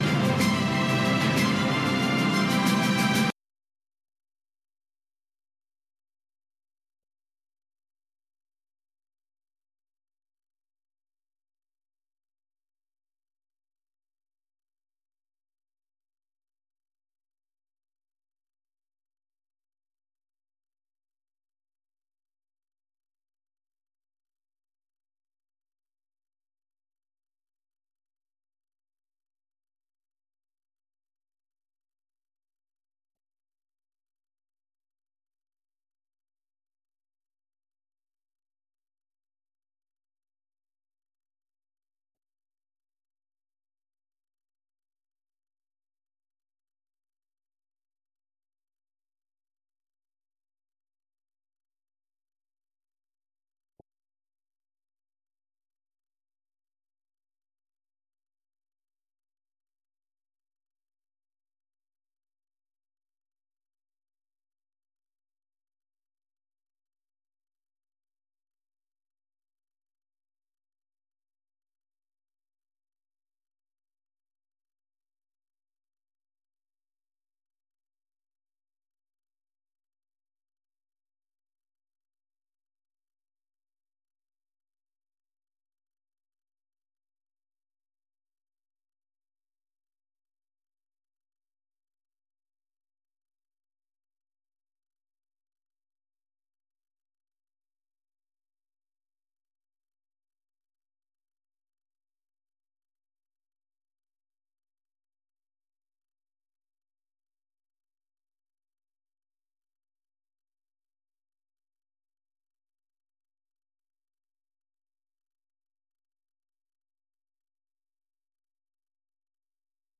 ሐሙስ፡-ከምሽቱ ሦስት ሰዓት የአማርኛ ዜና